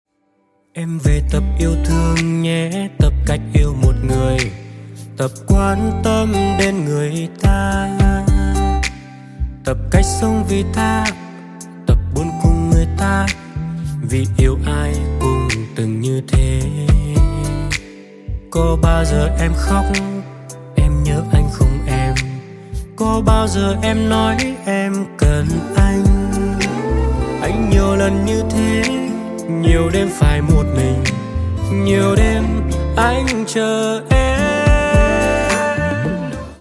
Nhẹ nhàng🥰